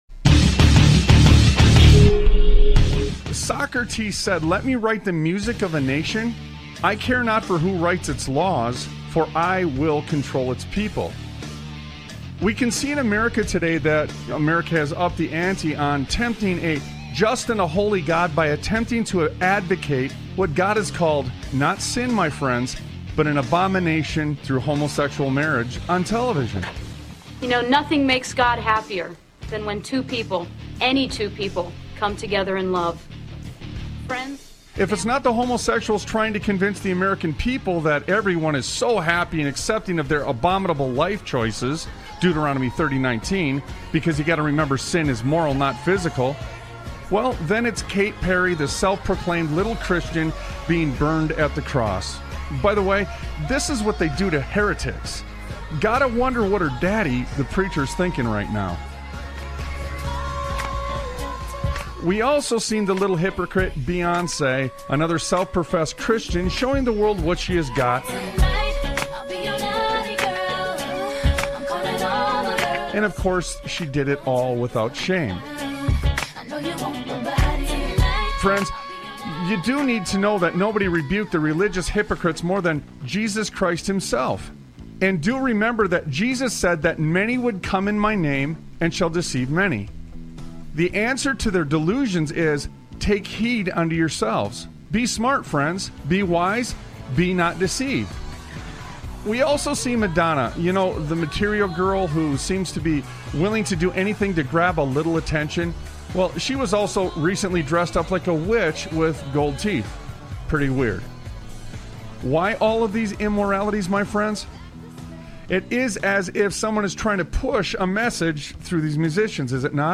Talk Show Episode, Audio Podcast, Sons of Liberty Radio and Hollywood: The Slough Of Mankind on , show guests , about Hollywood: The Slough Of Mankind, categorized as Education,History,Military,News,Politics & Government,Religion,Christianity,Society and Culture,Theory & Conspiracy